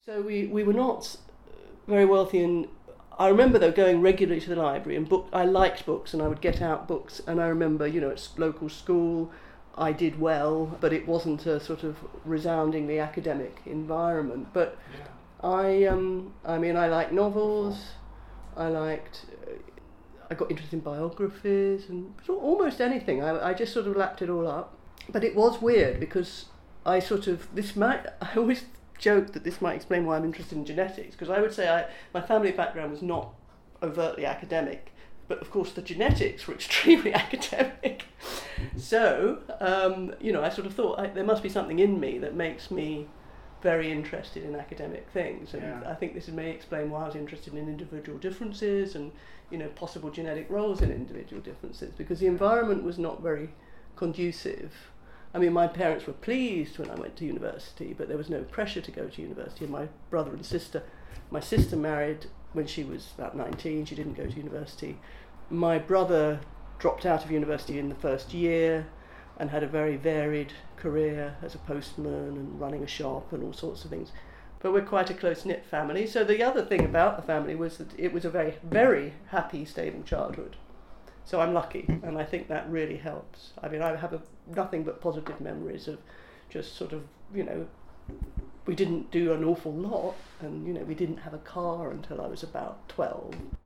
In this next excerpt Dr. Bishop recalls some of her happy life growing up in Ilford (1:30 min):